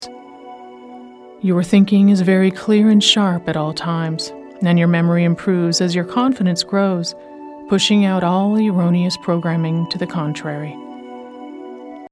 Increased Inner Power and Self Confidence MP3 is an empowering and insightful hypnosis recording that is part of our Reprogram Your Weight recording series.